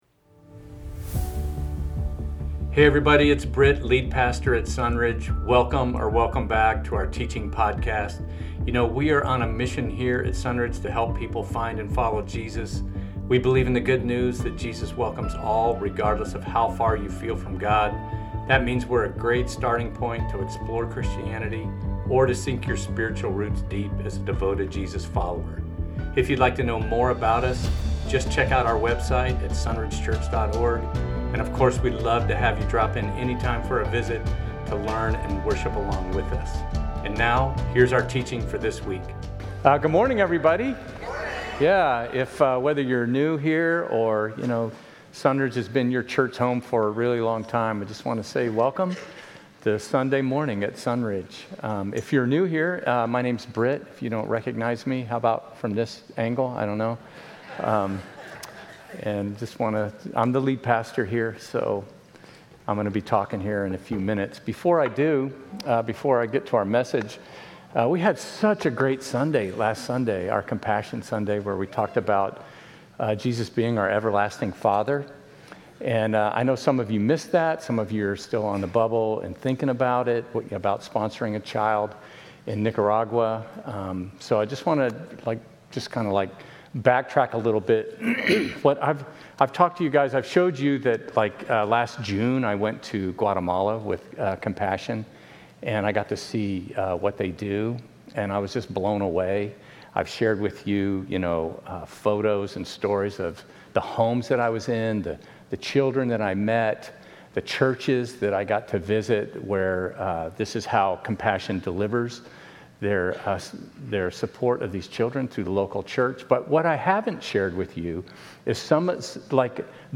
Prince of Peace - Sermons at Sunridge Church in Temecula.
Sermon Audio